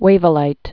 (wāvə-līt)